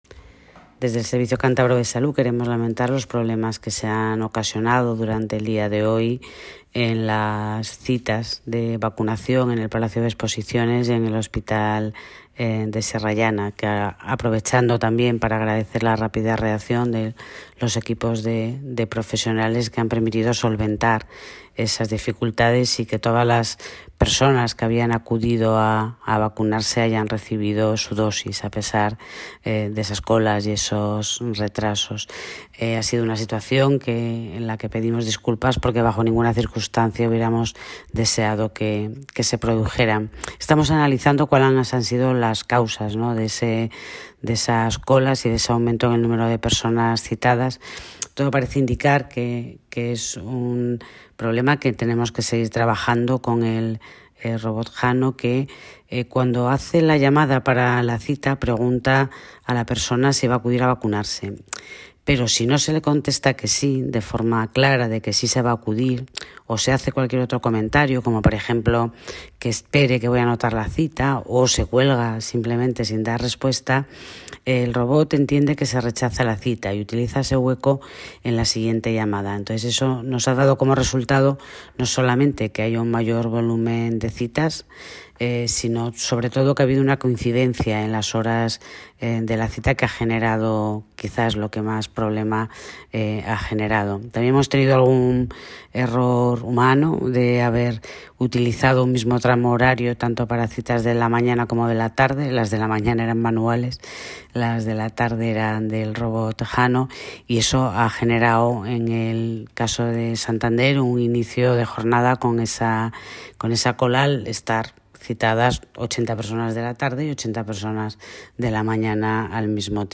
Audio de la Gerente del SCS Celia Gómez